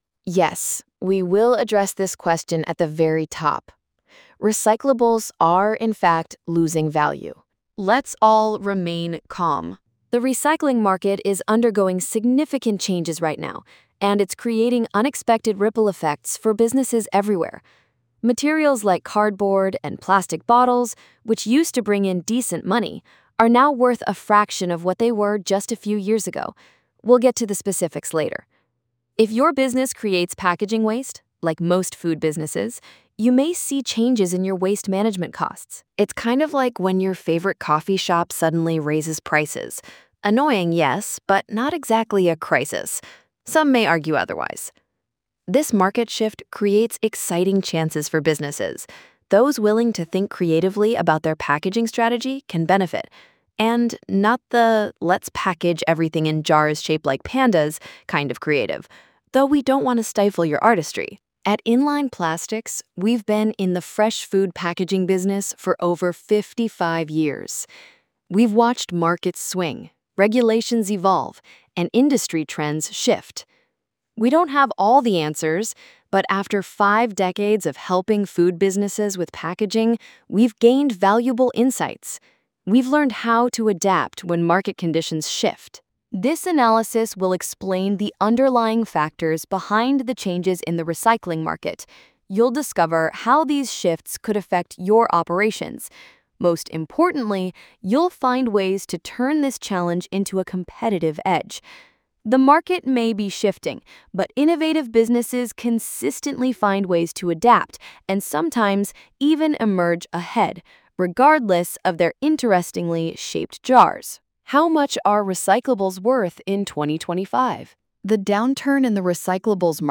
Are-Recyclables-Declining-in-Value-What-Food-Business-Professionals-Need-to-Know-Dictation.mp3